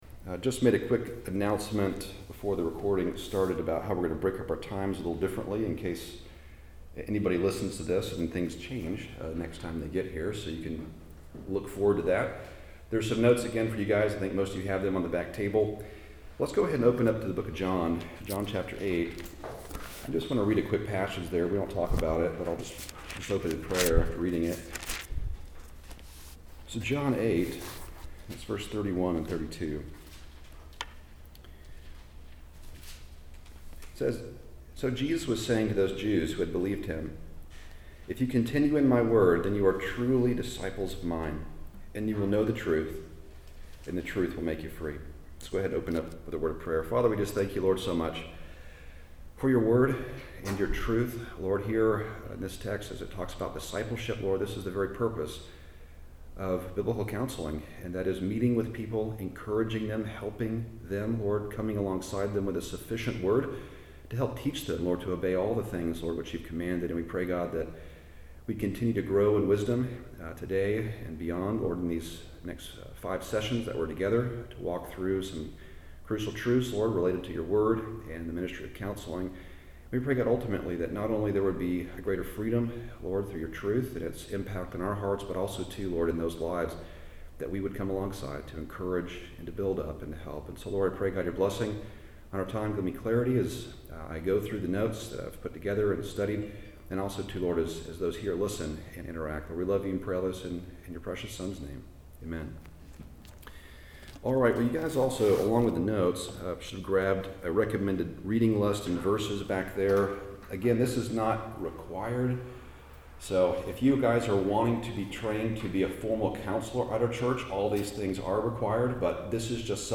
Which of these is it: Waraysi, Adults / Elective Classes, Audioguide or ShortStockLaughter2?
Adults / Elective Classes